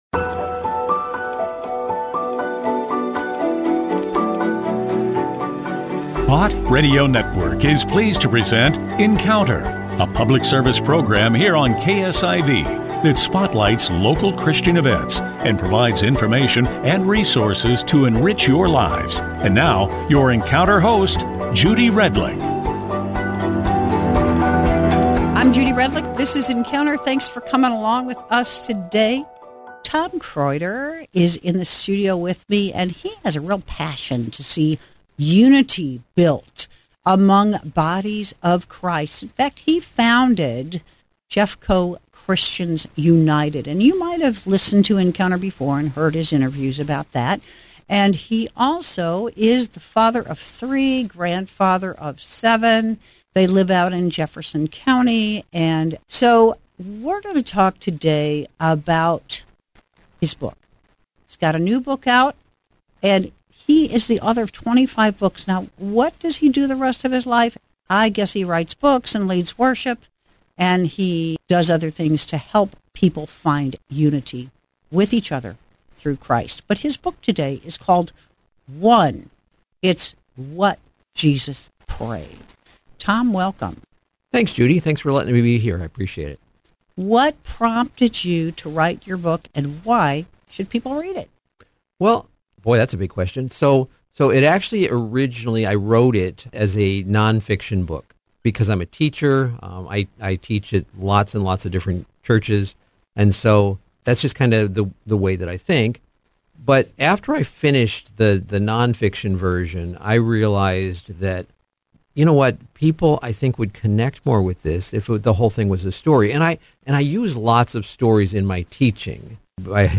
Encounter Show airs every Tuesday on KSIV radio, Bott Radio Network – St. Louis.